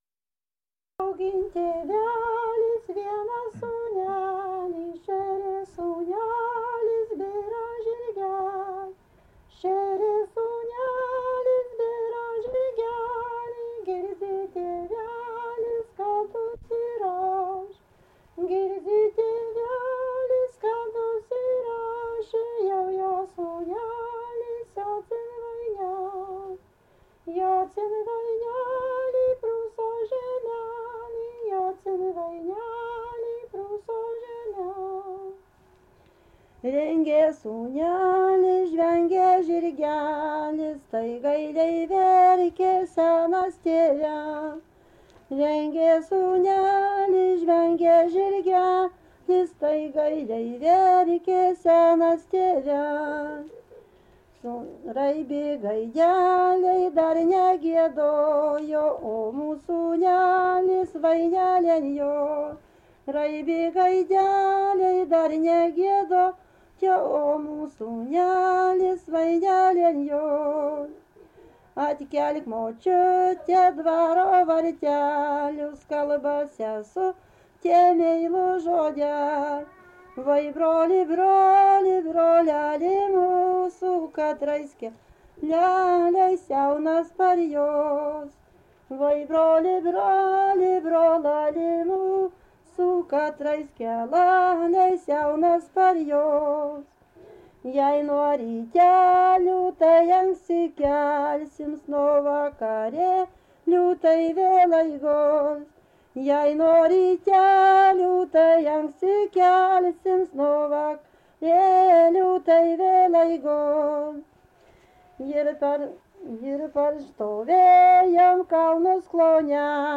daina, vestuvių
Atlikimo pubūdis vokalinis